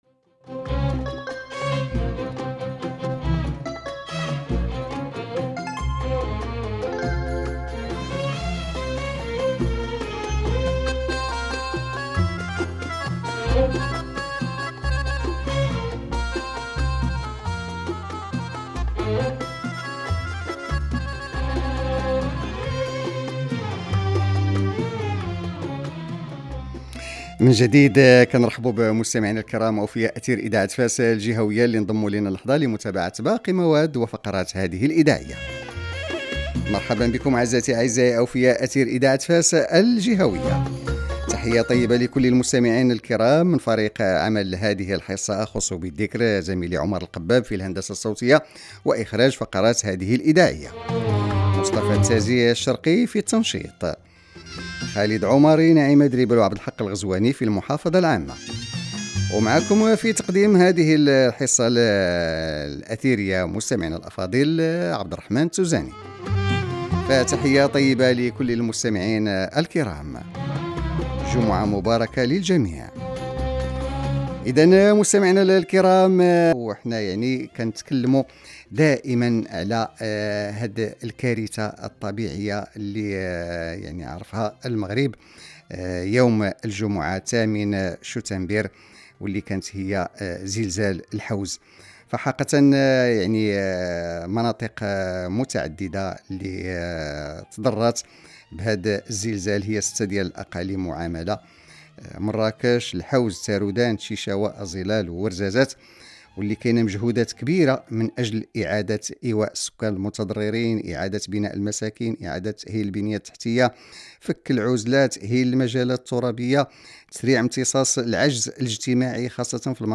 برنامج إذاعي حول تراجع صبيب مياه عين بطيط بإقليم الحاجب